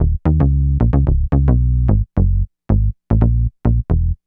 Bass 32.wav